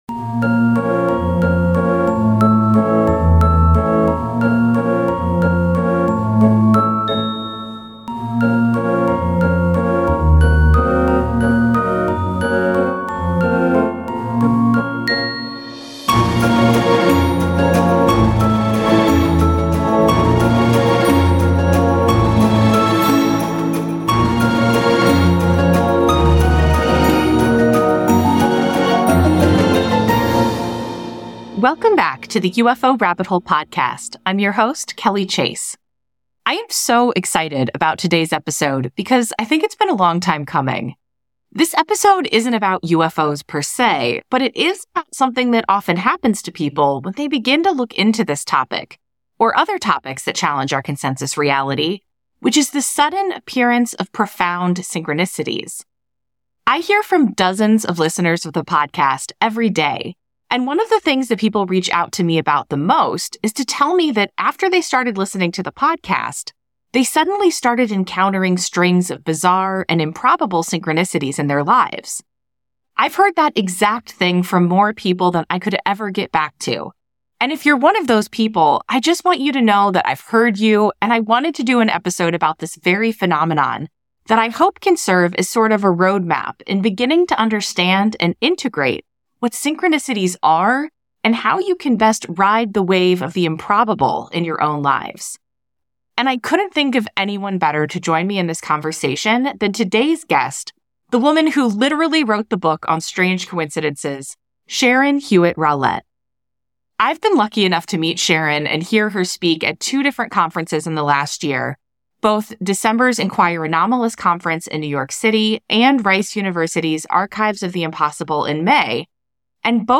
[The UFO Rabbit Hole] An Interview